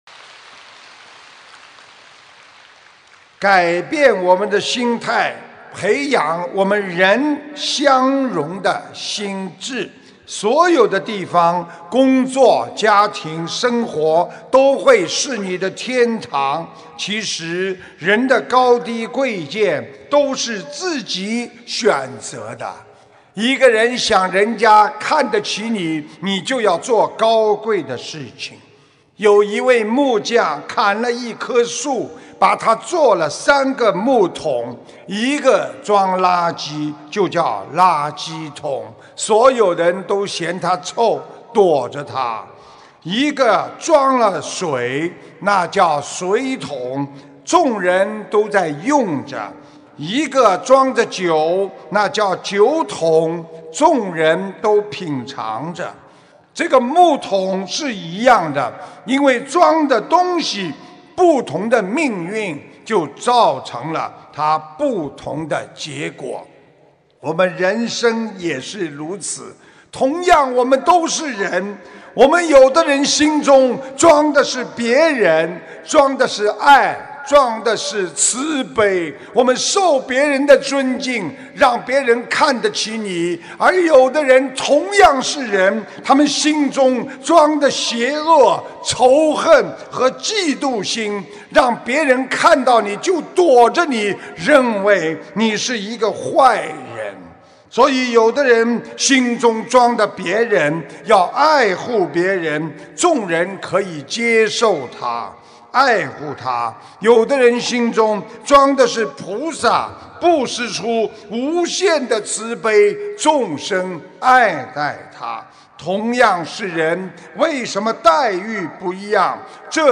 音频：木匠与三个木桶！2019年9月10日荷兰鹿特丹